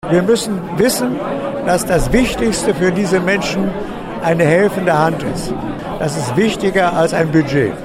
Rupert Neudeck wurde einmal "Extremist in Sachen Nächstenliebe" genannt, uns hat er dazu vor zehn Jahren Folgendes gesagt.